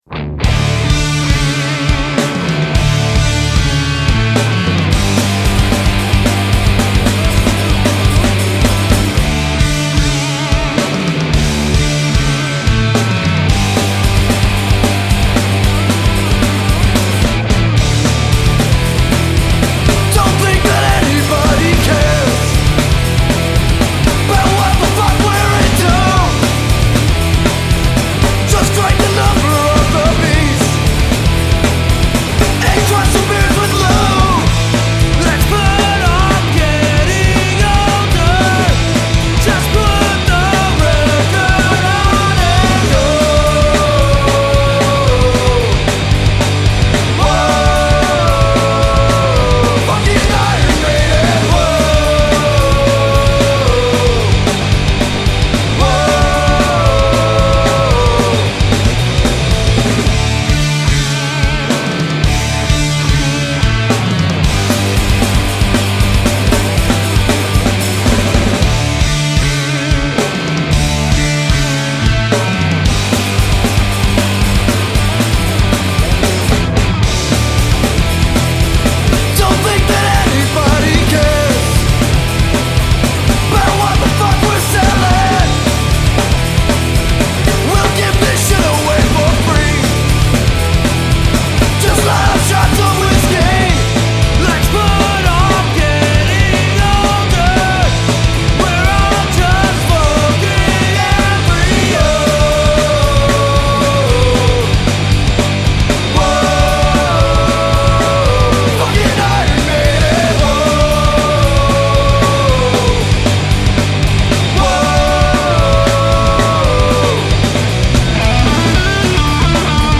melodic punk band